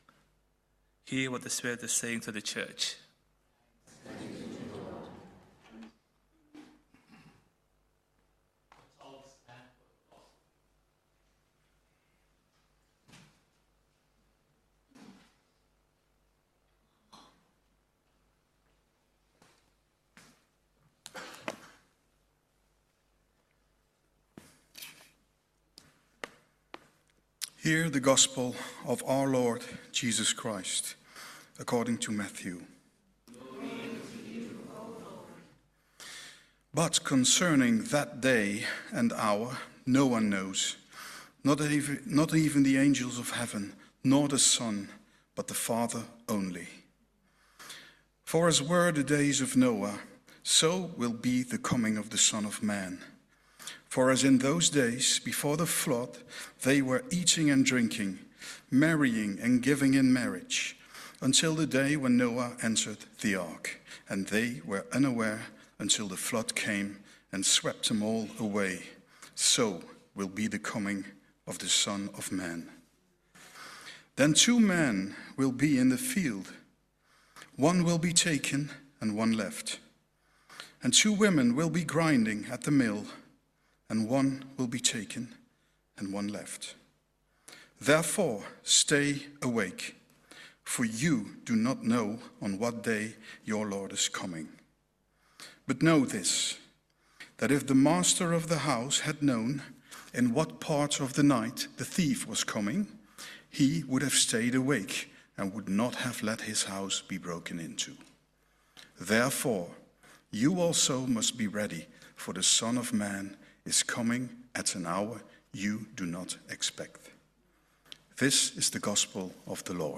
This sermon centers on the Blessed Hope that shapes the faith of God’s people across the ages.